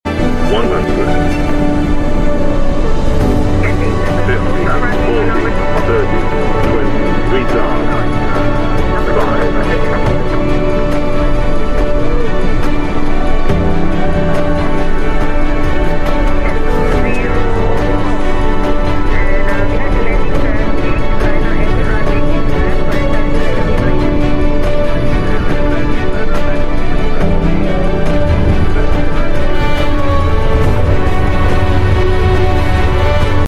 2 different views of 9M-MNK delivery flight.